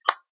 switch11.ogg